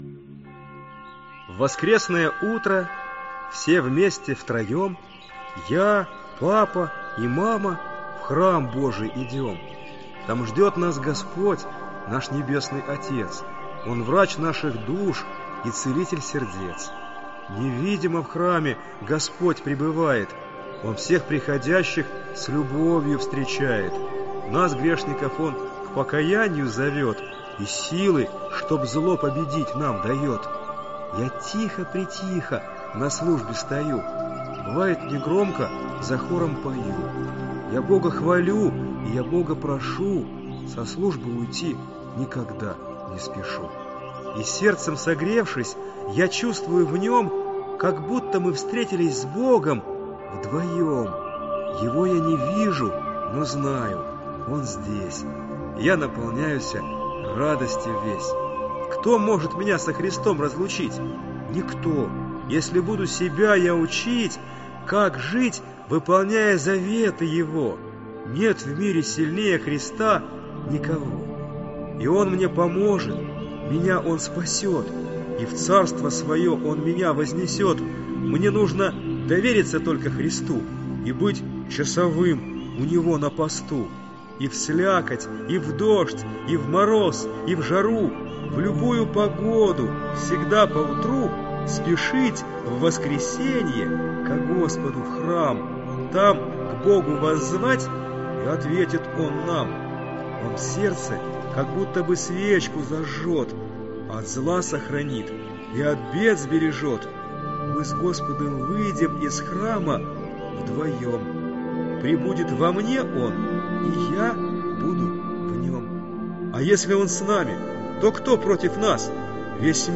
Аудиокнига Православное детство. Катехизис в стихах для детей | Библиотека аудиокниг